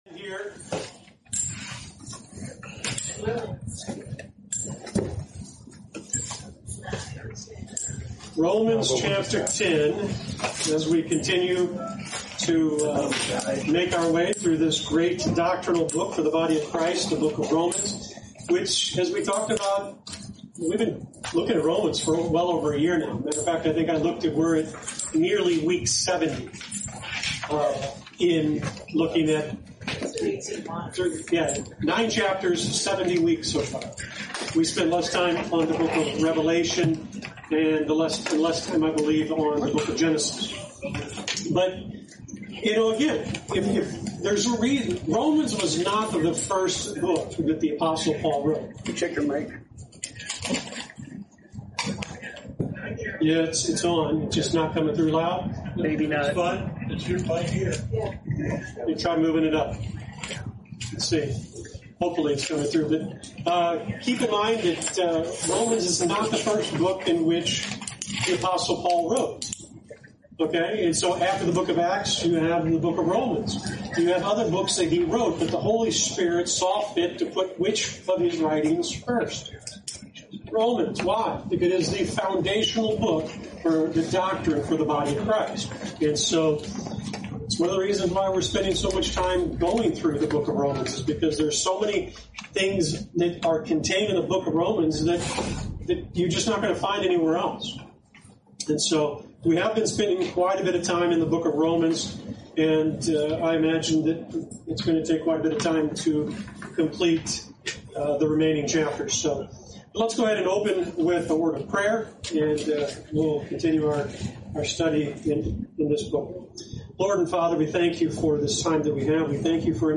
You're listening to Lesson 63 from the sermon series "Romans